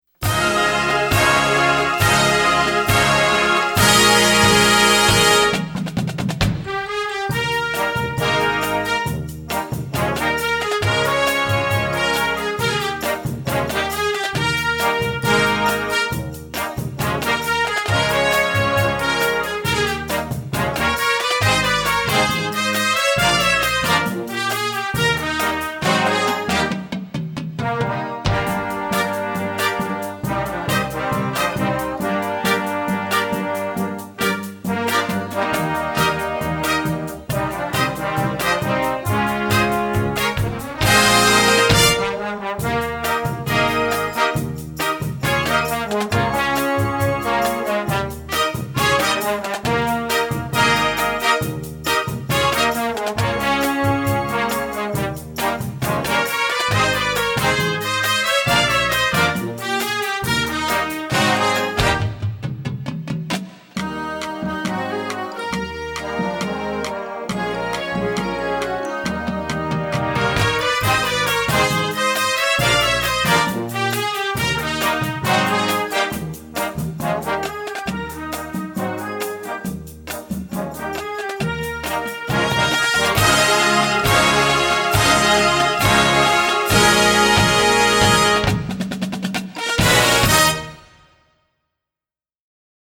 Marching-Band
Besetzung: Blasorchester